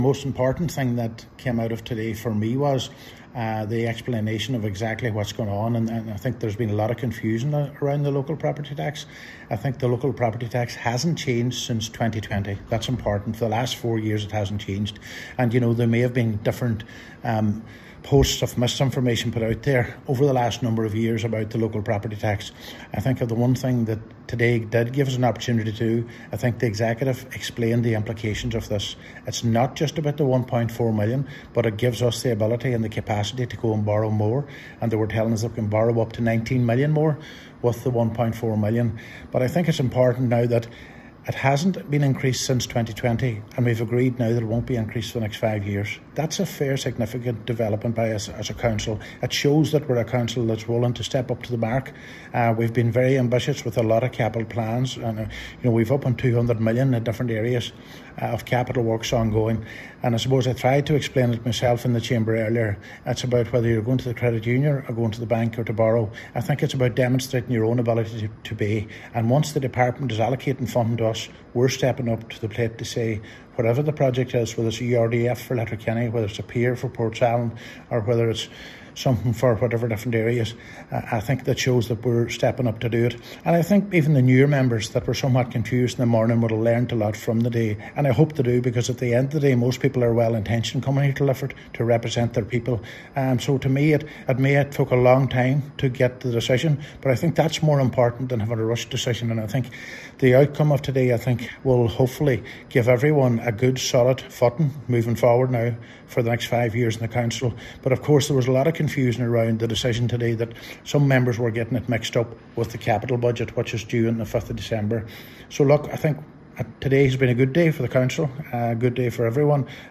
The rate will now be retained for the next five years, something Councillor Ciaran Brogan says is significant: